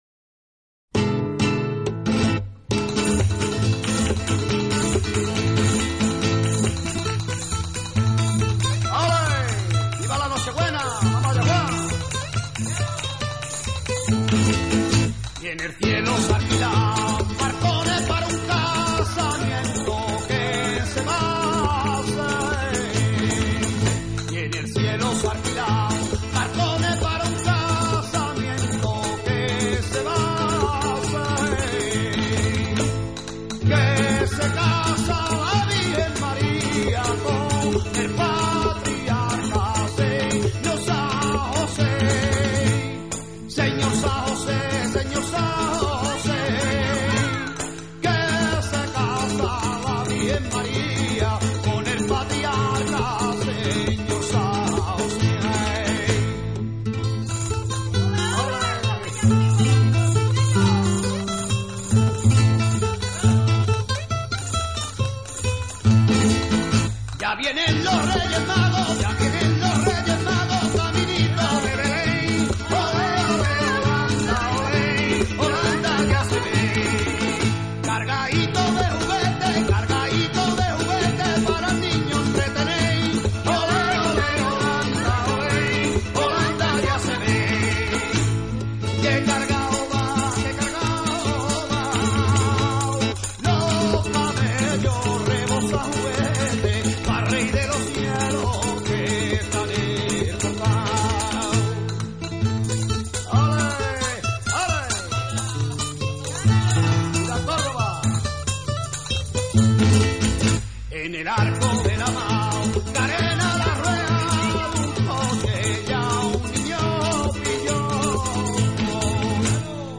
佛拉门戈吉他